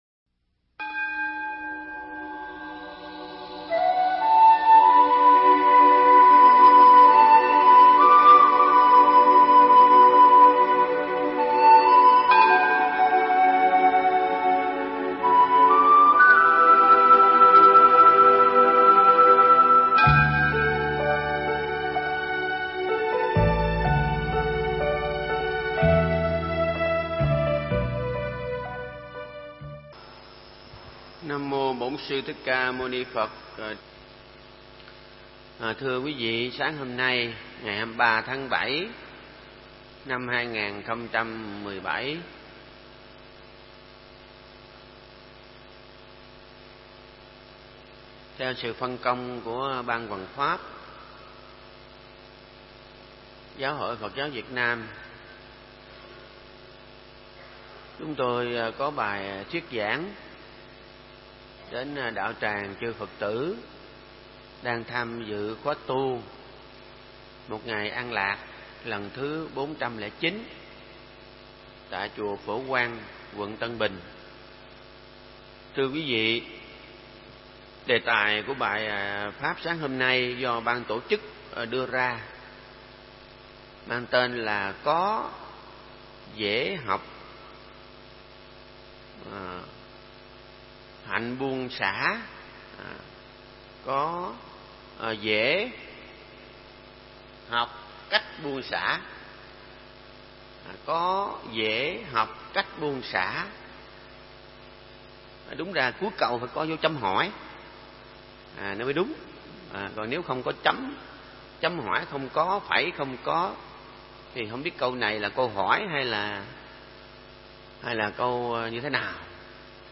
Mp3 Thuyết Pháp Có dễ học cách buông xả
khóa tu an lạc lần thứ 409 chùa Phổ Quang, Quận Tân Bình, TPHCM